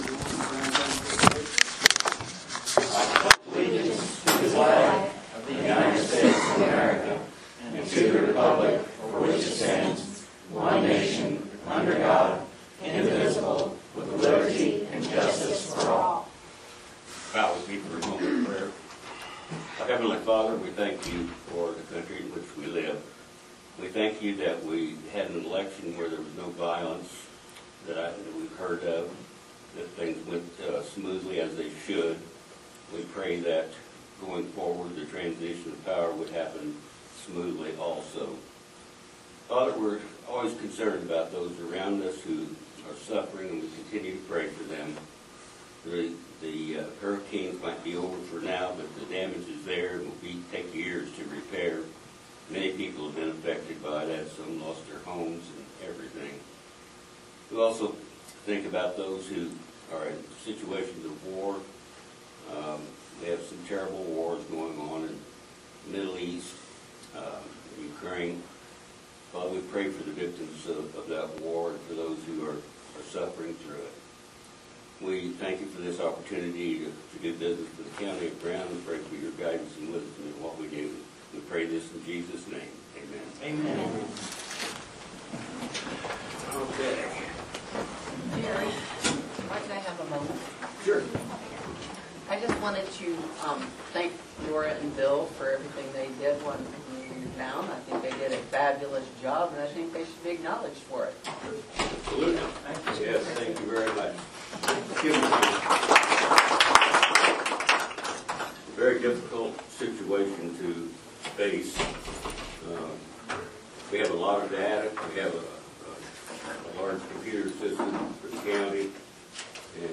Dec 4, 2024. AUDIO: Commissioner Meeting